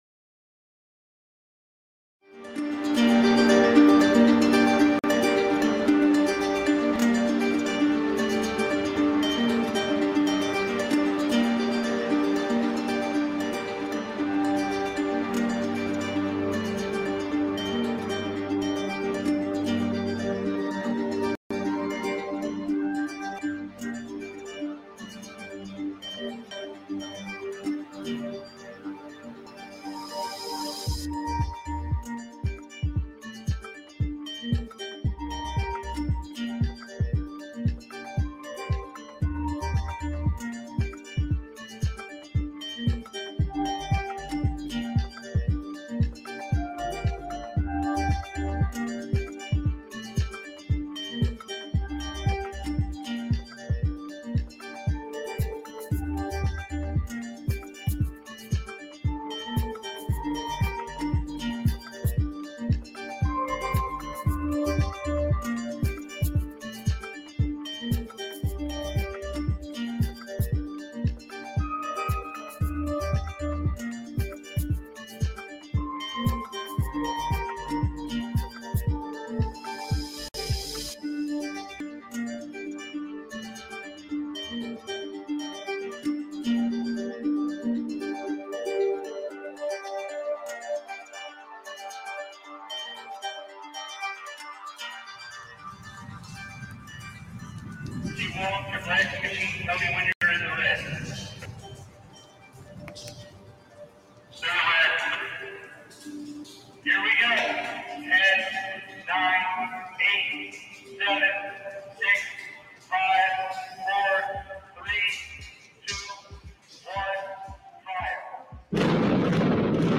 Fakeologist show Live Sun-Thu 830pm-900pm EDT